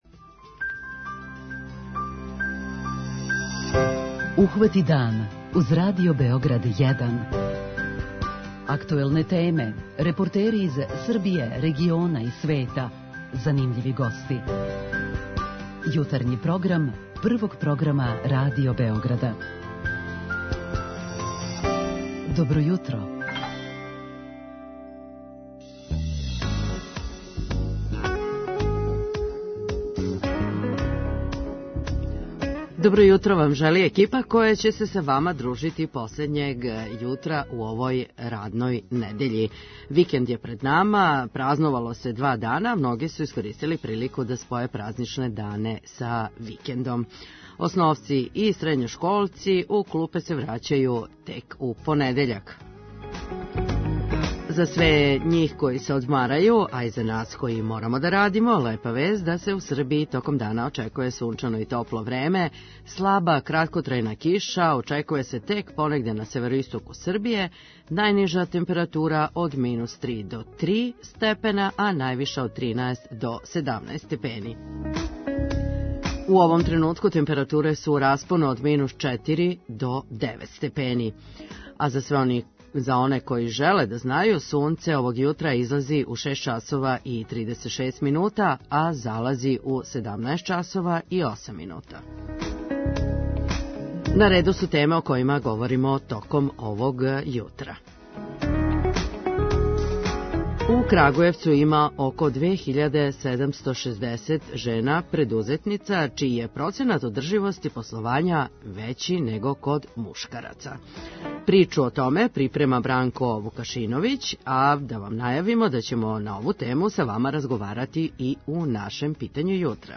То ће бити и тема нашег данашњег питања јутра. преузми : 37.78 MB Ухвати дан Autor: Група аутора Јутарњи програм Радио Београда 1!